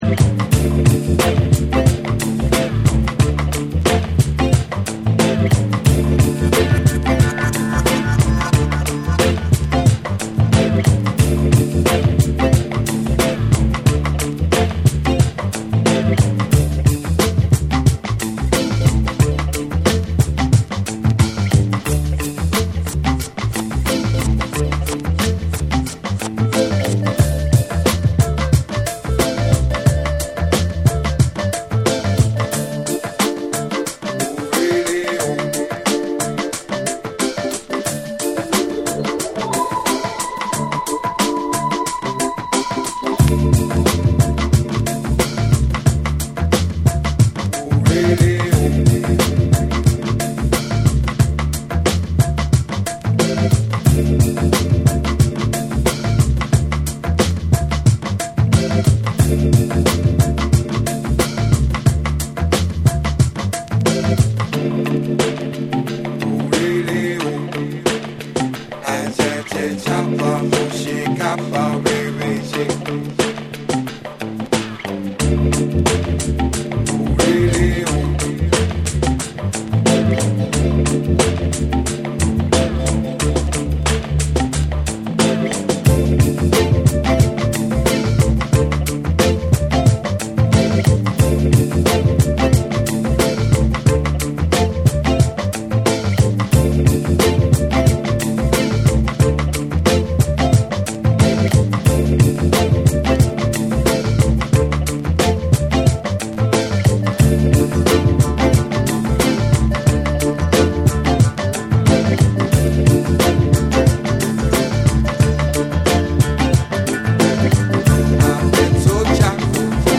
ダビーなベースラインが深く響き渡り、レゲエやダブのエッセンスを感じさせるブレイクビーツ
BREAKBEATS / ORGANIC GROOVE